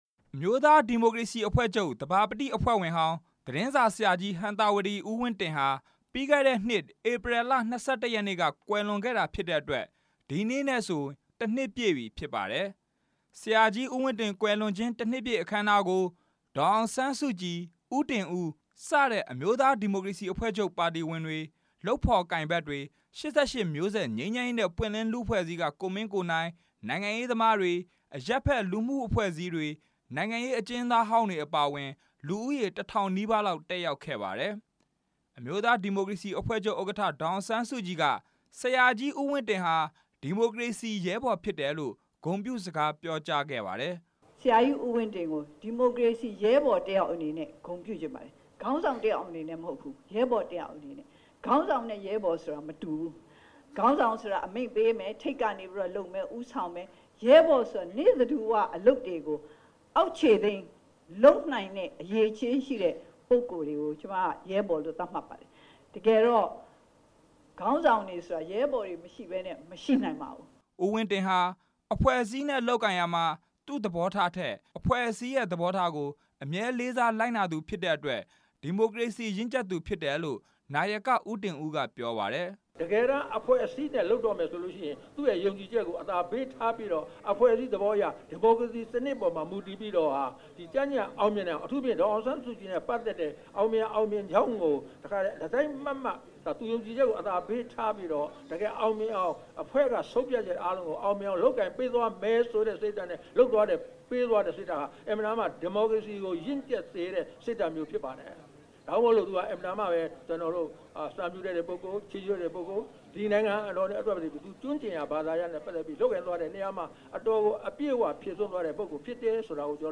အမျိုးသားဒီမိုကရေစီအဖွဲ့ချုပ် သဘာပတိအဖွဲ့ဝင်ဟောင်း သတင်းစာဆရာကြီး ဦးဝင်းတင် ကွယ်လွန်ခြင်း တစ်နှစ်ပြည့်အခမ်းအနားကို ရန်ကုန်မြို့ ရွှေဂုံတိုင် လမ်းပေါ်ရှိ တော်ဝင်နှင်းဆီခန်းမမှာ ဒီနေ့ ပြုလုပ်ခဲ့ပါတယ်။
ဒီနေ့အခမ်းအနားကို အမျိုးသားဒီမိုကရေစီအဖွဲချုပ်ဥက္ကဌဒေါ်အောင်ဆန်းစုကြည်နဲ့ နာယက ဦးတင်ဦးအပြင် ကိုမင်းကိုနိုင်တို့က အမှတ်တရစကားပြောခဲ့ကြ ပါတယ်။